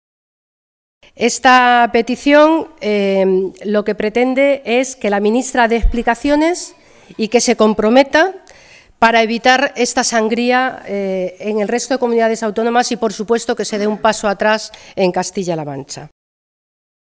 Martín hacía estas declaraciones en una comparecencia ante los medios de comunicación en la que informaba, además, de que el Grupo parlamentario socialista en el Congreso de los Diputados ha registrado la petición de comparecencia de la ministra de Sanidad, Ana Mato, en la comisión de Sanidad para que explique el cierre nocturno de los veintiún Puntos de Atención Continuada (PAC) en Castilla-La Mancha.
Cortes de audio de la rueda de prensa